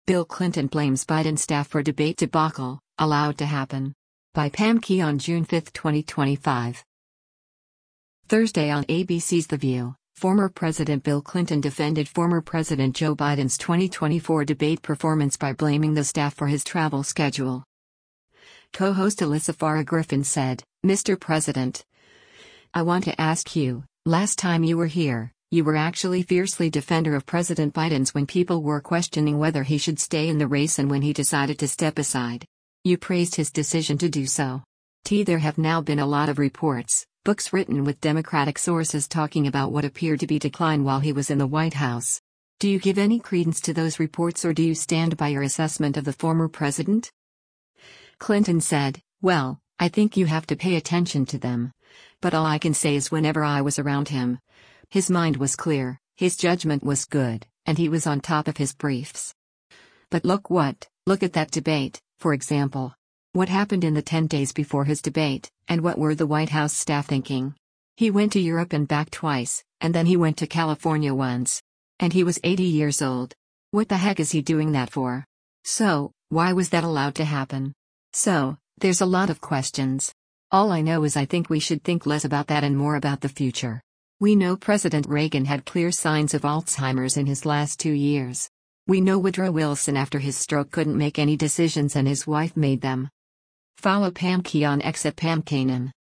Thursday on ABC’s “The View,” former President Bill Clinton defended former President Joe Biden’s 2024 debate performance by blaming the staff for his travel schedule.